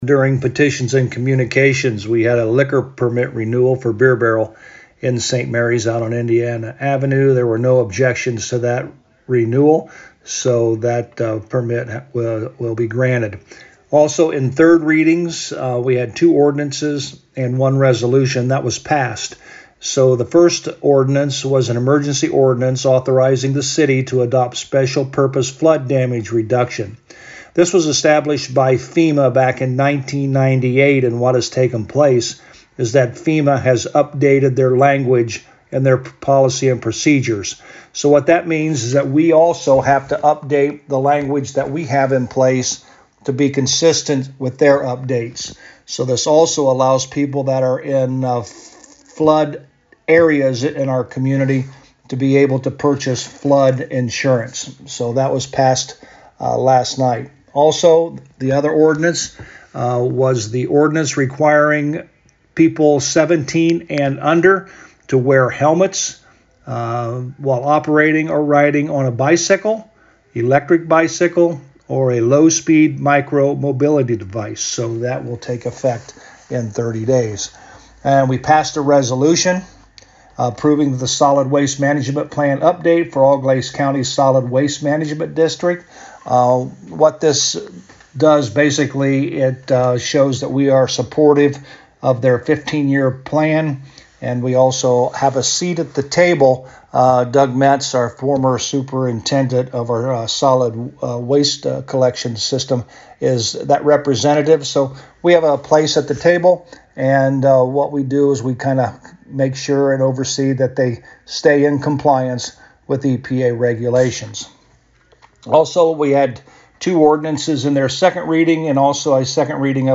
To hear the audio recap with Mayor Hurlburt: